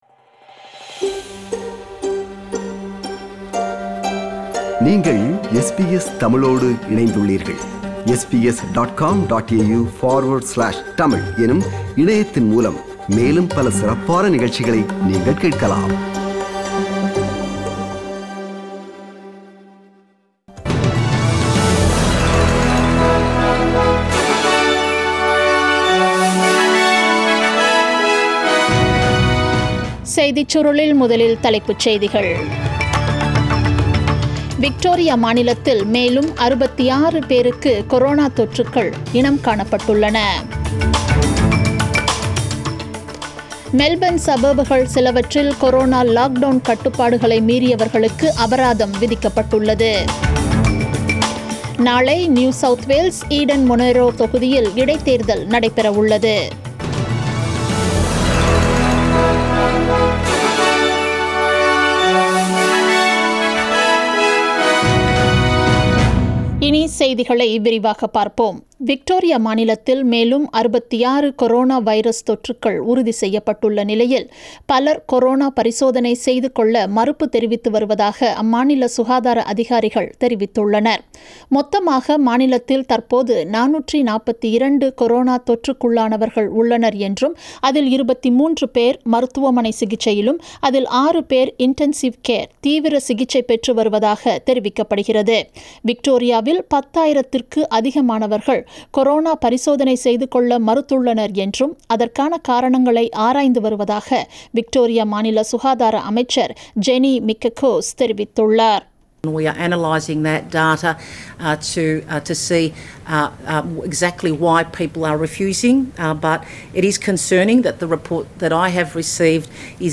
The news bulletin aired on 03 July 2020 at 8pm